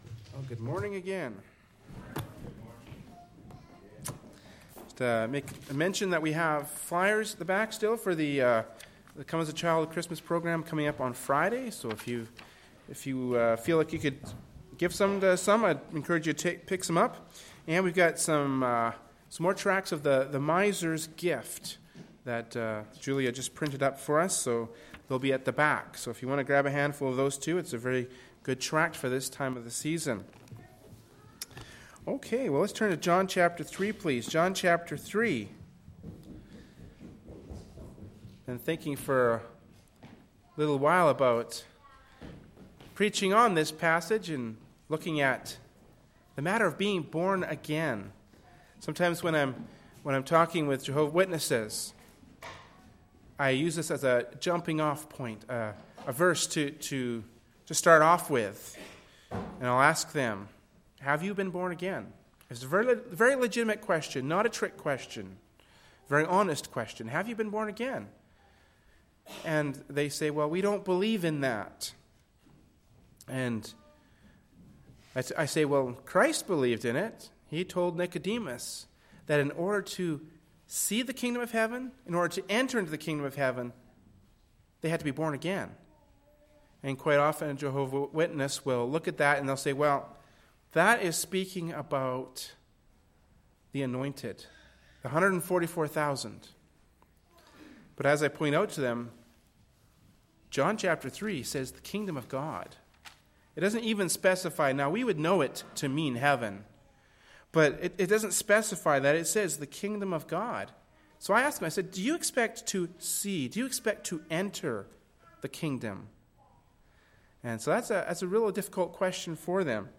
“John 3:1-8” from Sunday Morning Worship Service by Berean Baptist Church.